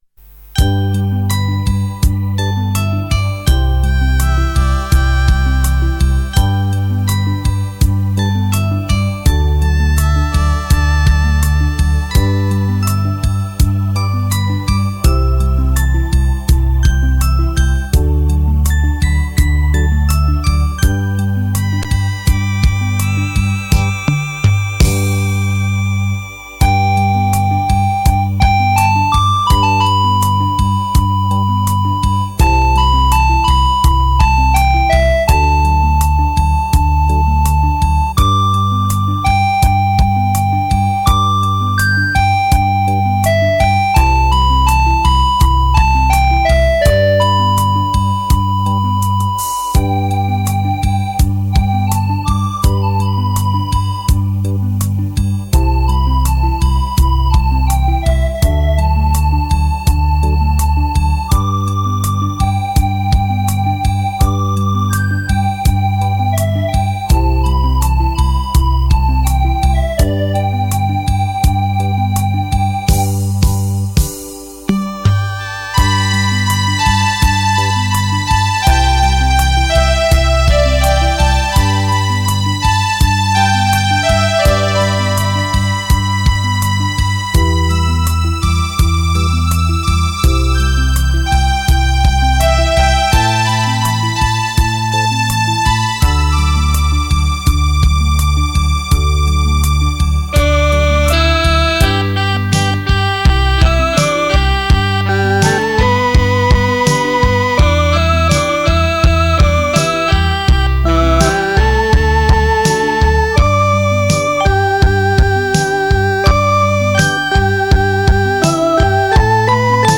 纯净晶莹 柔情似水的乐曲